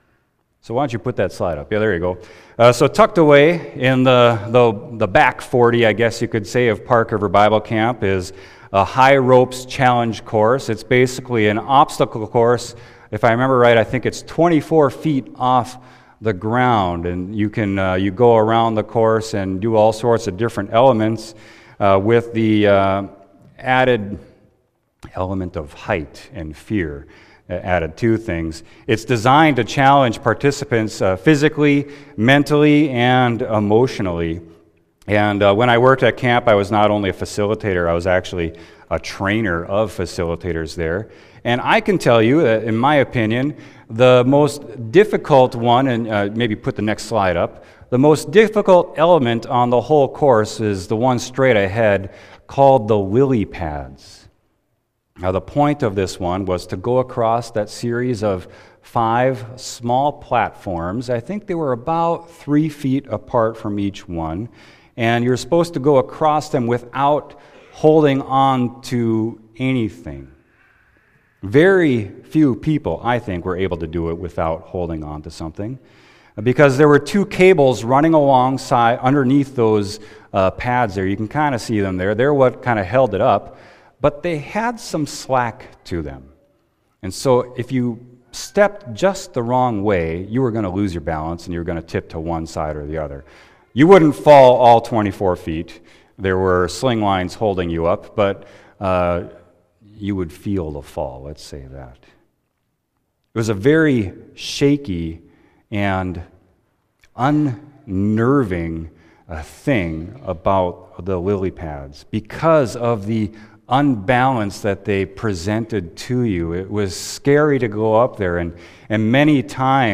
Sermon: Psalm 46